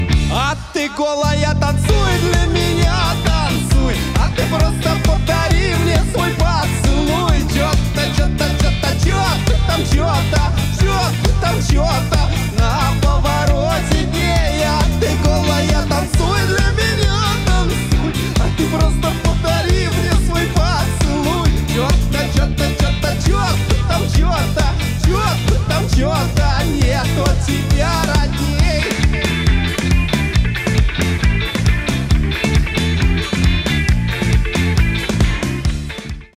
• Качество: 320, Stereo
мужской голос
прикольные